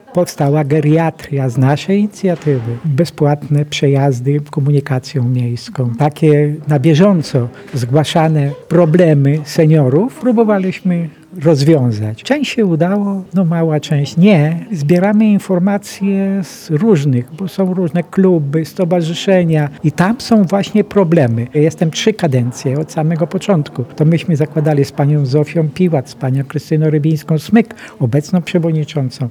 10-lecie działalności świętowała dziś (18.09) Zamojska Rada Seniorów. Z tej okazji w ratuszu zorganizowano uroczystą sesję jubileuszową – z okazałym tortem i wspomnieniami.